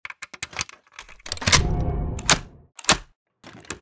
Plastic_Small_Key.ogg